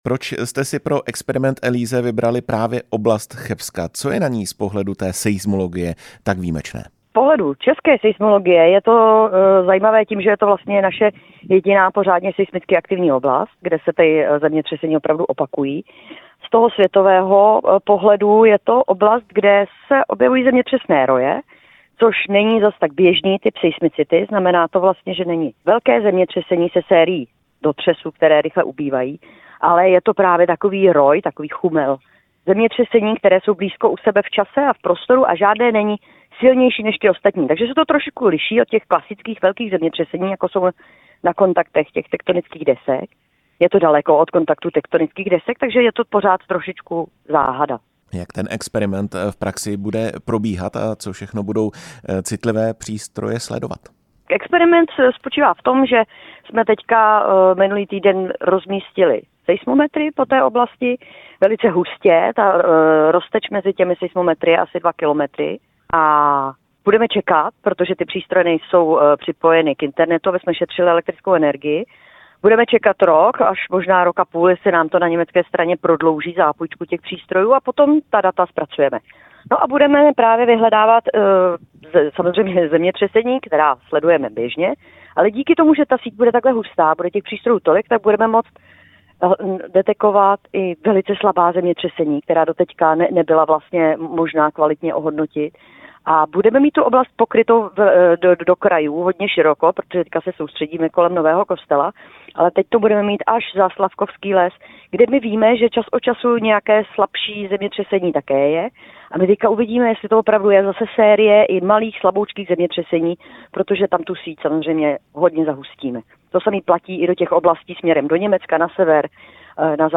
Rozhovor s vědkyní